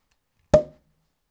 • beer popping cork sound.wav
Opening a bottle of Abbaye de Vauclair in a summer kitchen, accompanied by a peaceful spring sunset, recorded with a Tascam DR 40.
beer_popping_cork_sound_1PM.wav